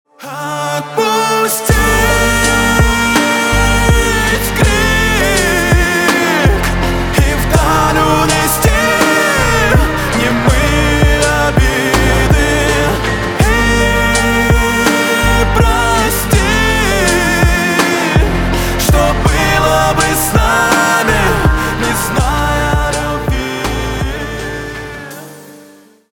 на русском грустные про любовь